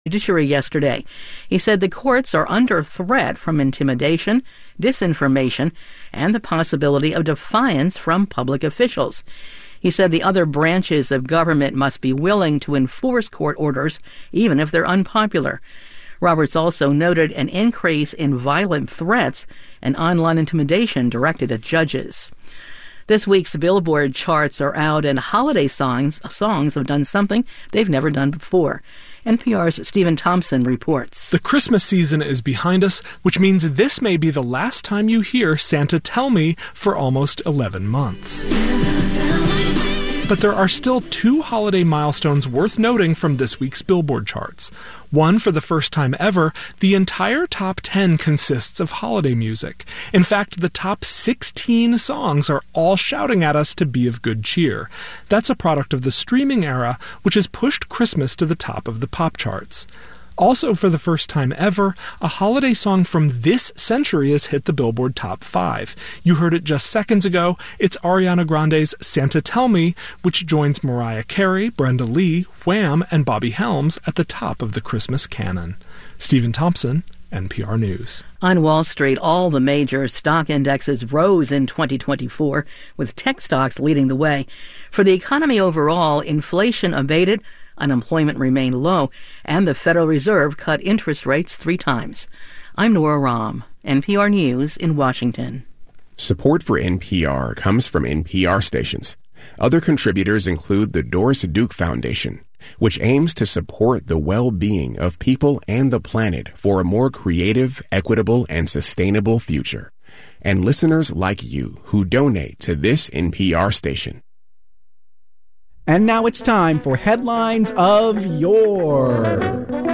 WHYY Newscast